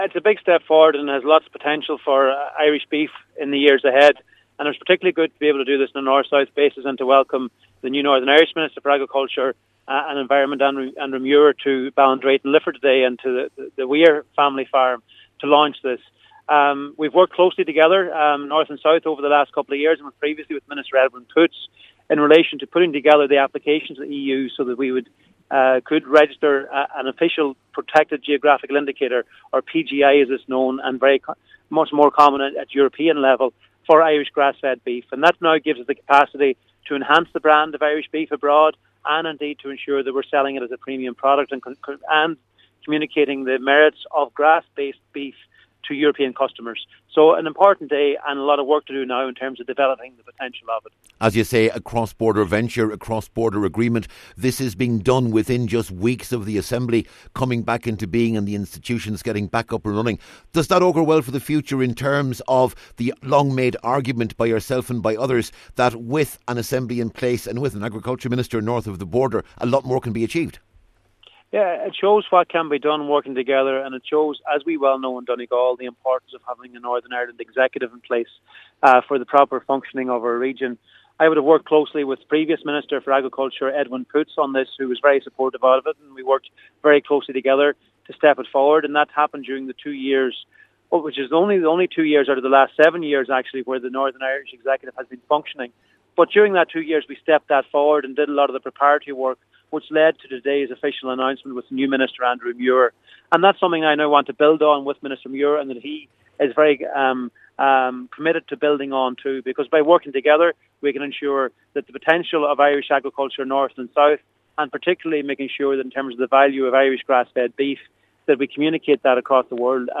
MInister McConalogue says this is an indication of what can be achieved when the institutions are in place, and two ministers can discuss mutually beneficial strategies…….